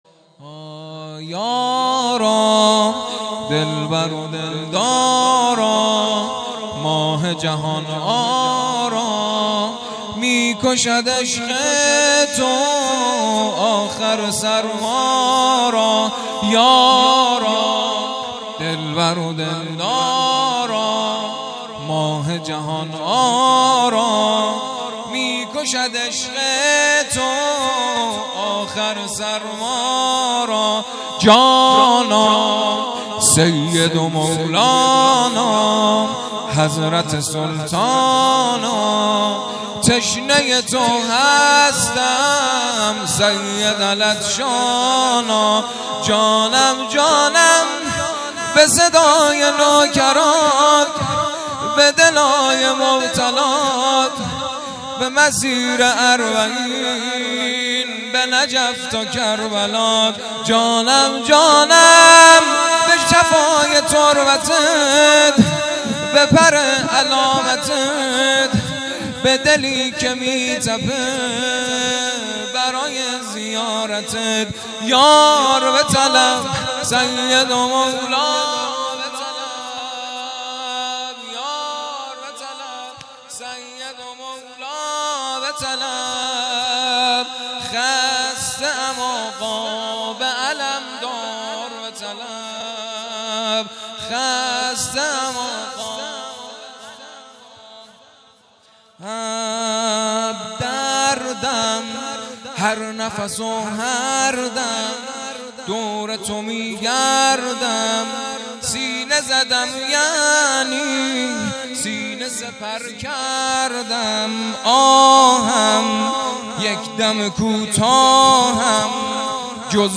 زمینه یارا دلبر و دلدارا